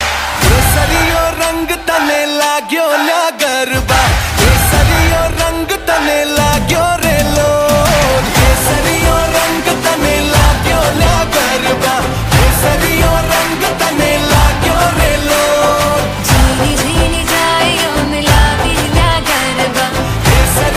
Garba Ringtone For Android and iPhone mobiles.